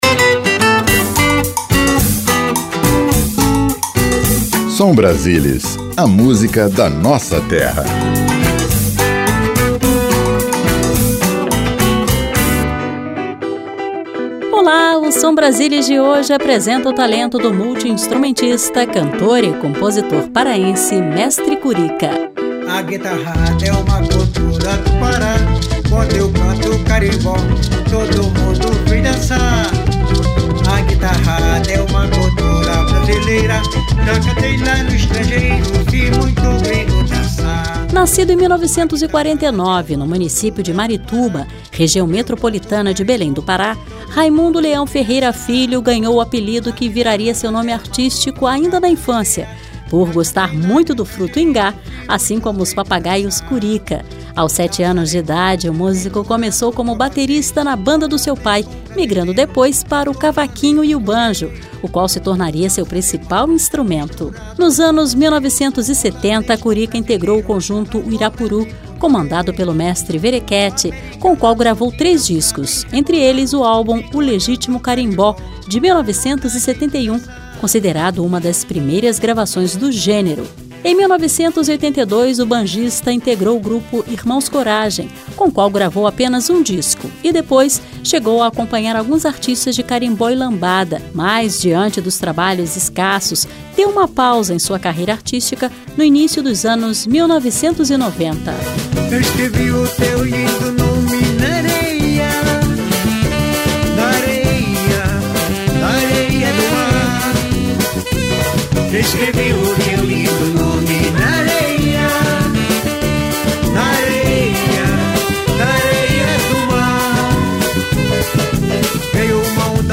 Seleção Musical: